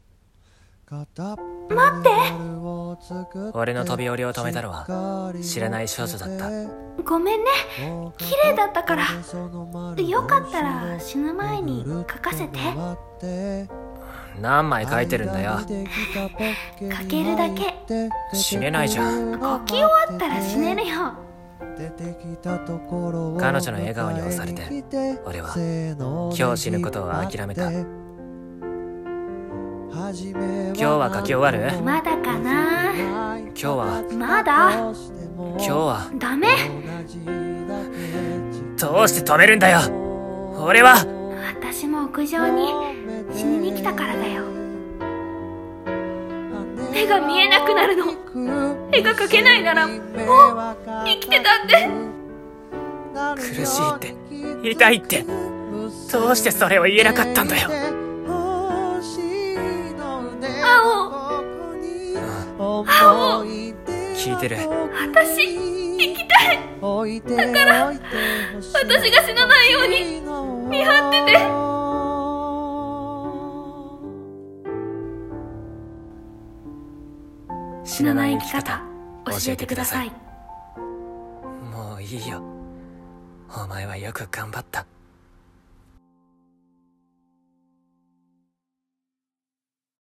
【映画予告風声劇】死なない生き方、教えてください。